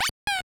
Yoshi sound effect in Super Mario Bros. Deluxe